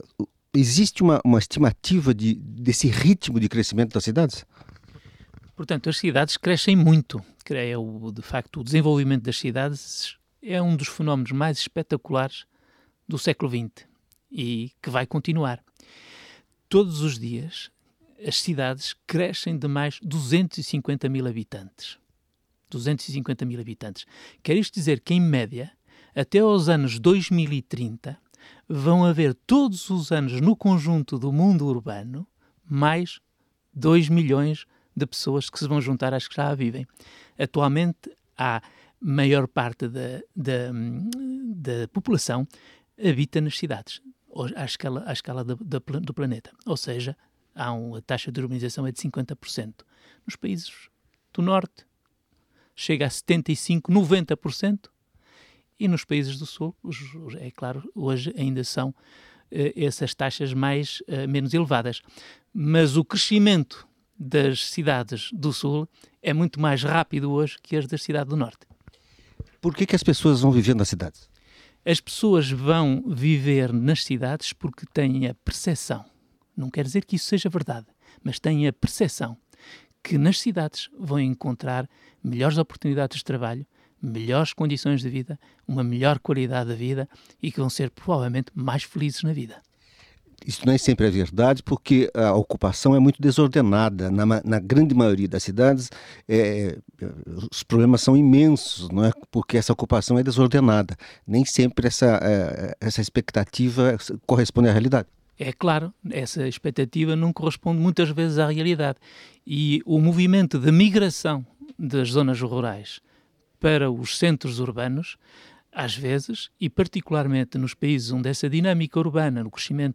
Mesmo assim, é possível e necessário melhorar a qualidade de vida da população urbana. Ouça a entrevista